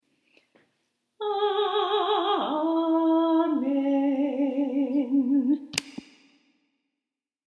Certain melodic formulas repeat over and over throughout the High Holidays - for example special cadences such as the way we sing "
HHD Amidah Amen_0.mp3